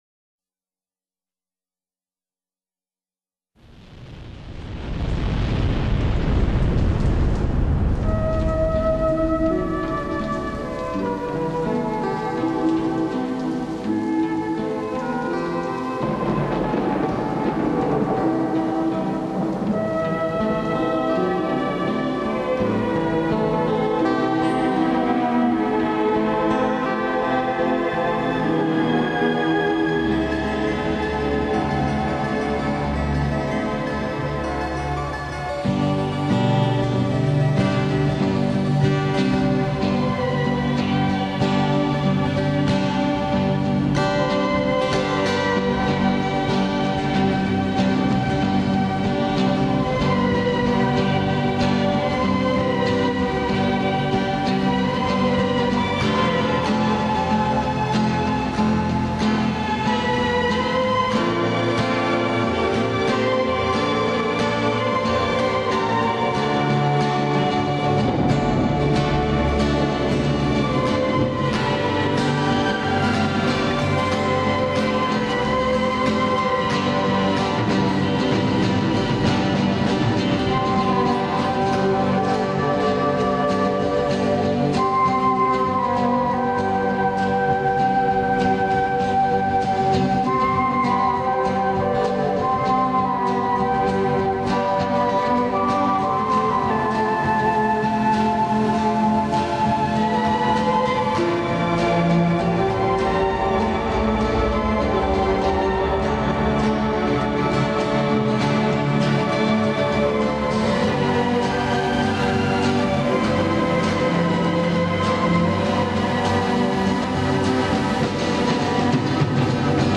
音乐四季，自然感悟！
大自然的悸动之鸣，悦动心弦的妙韵旋律！